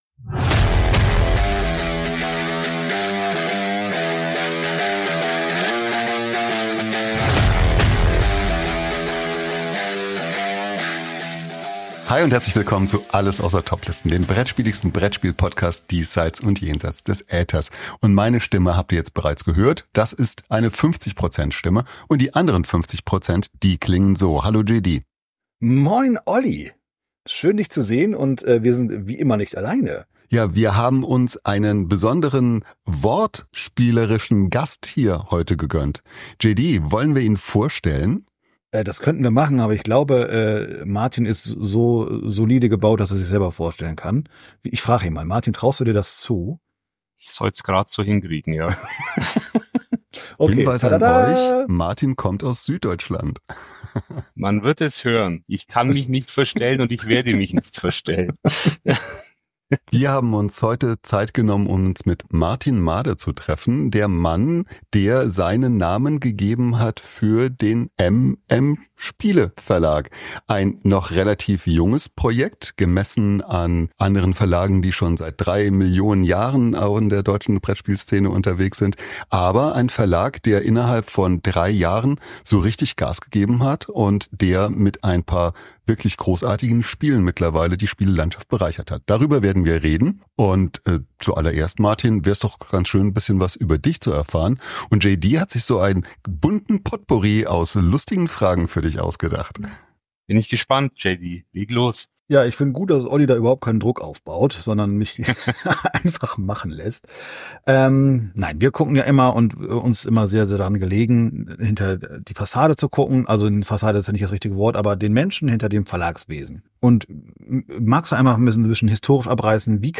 Interviewfolge